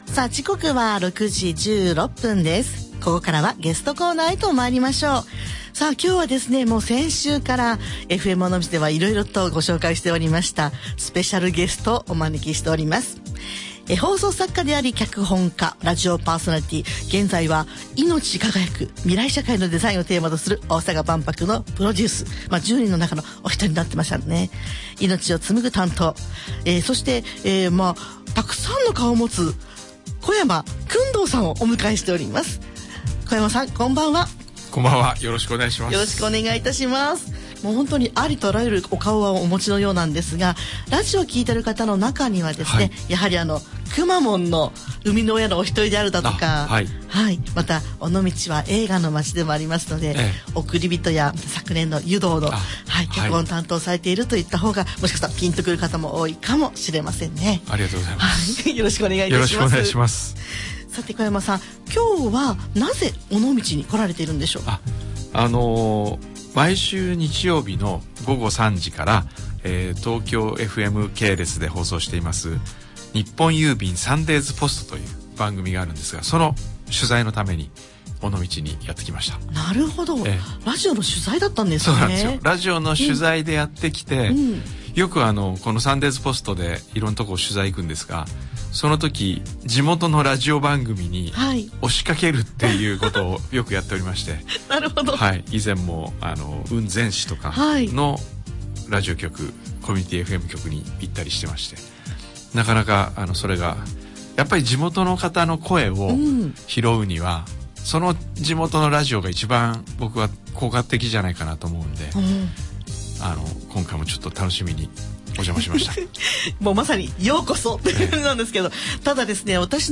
今月末に開業する尾道倶楽部をプロデュースする、放送作家で脚本家の小山薫堂さんがFMおのみちに生出演。